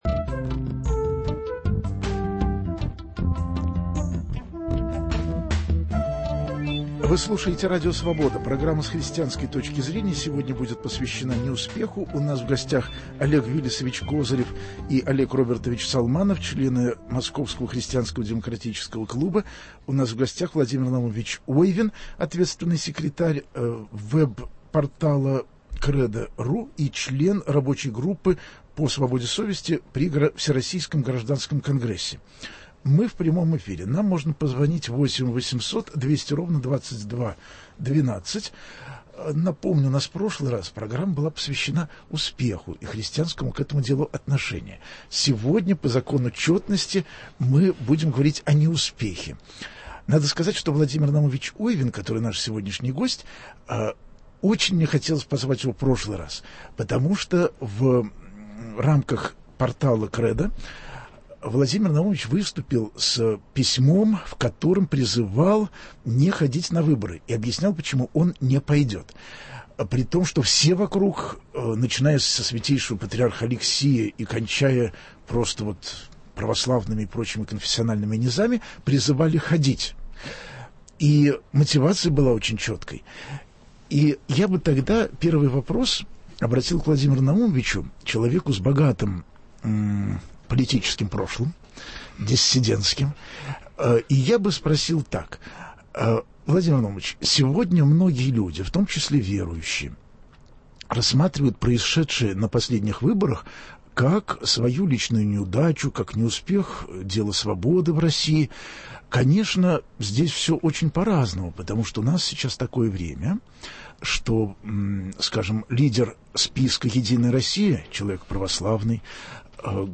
В передаче примут участие христиане разных конфессий